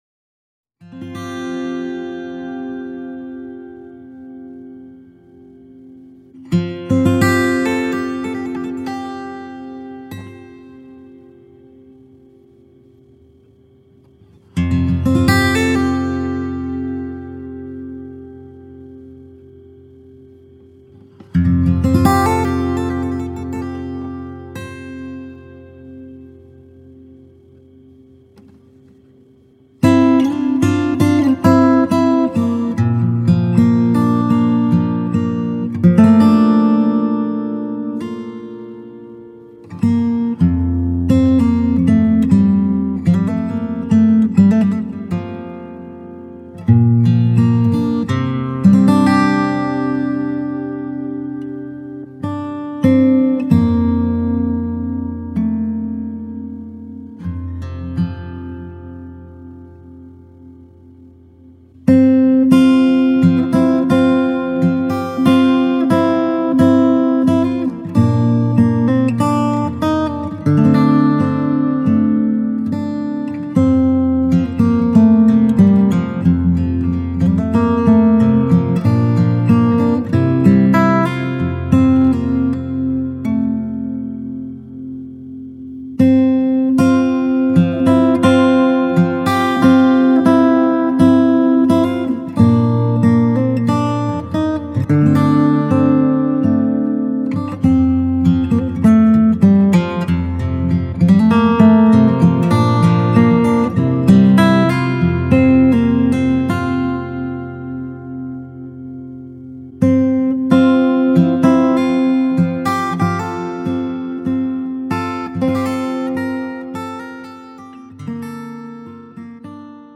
An acoustic instrumental journey
Bourgeois OM Luthier Series rosewood/spruce guitar